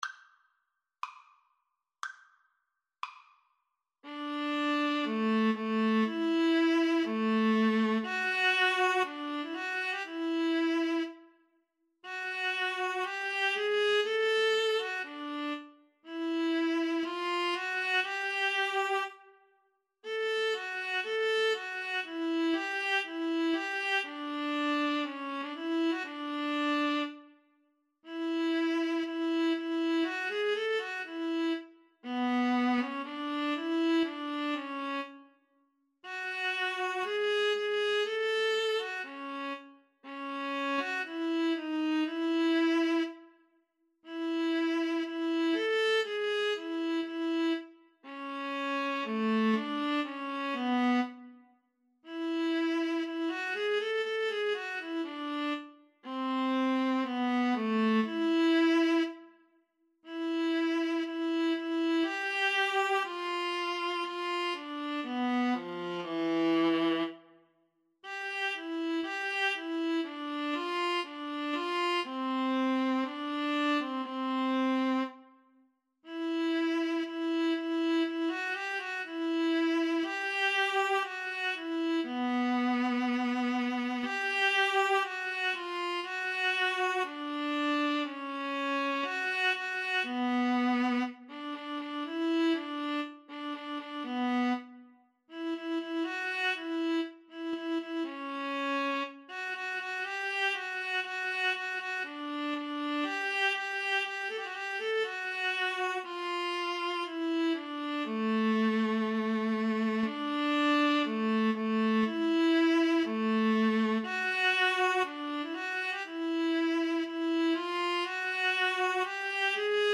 adagio Slow =c.60
Classical (View more Classical Viola Duet Music)